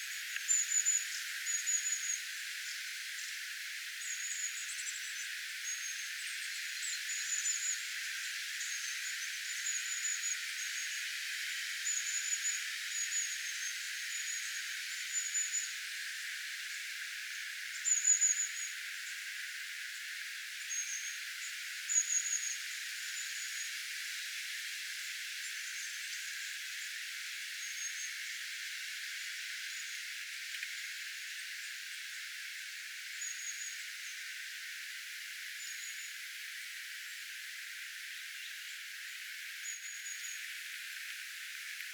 pyrstötiaisparvi
pyrstotiaisparven_aantelya_ehka_muuttava.mp3